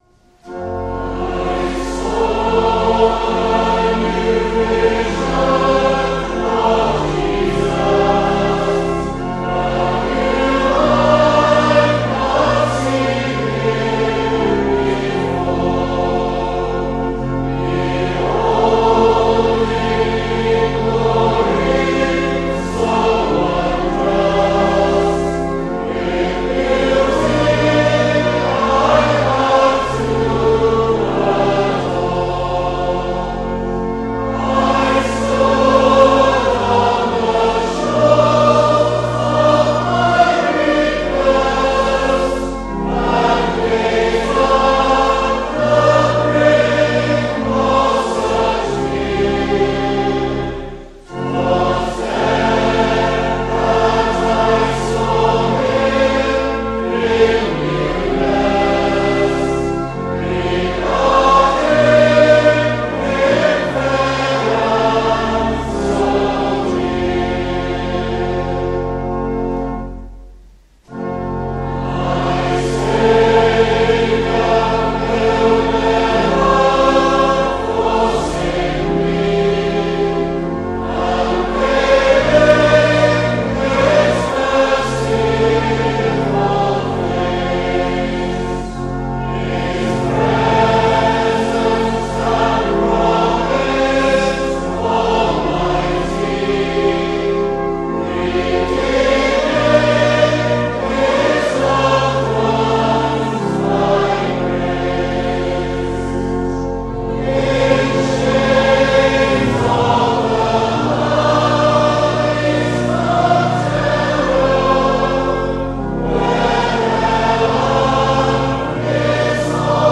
AudioTrack 04-Summer-Conference-Congregation-I-saw-a-new-vision.mp3